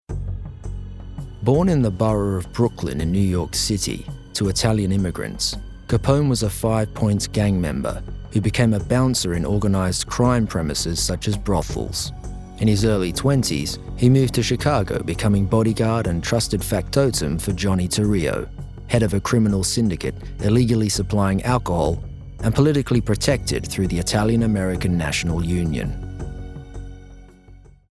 Documentary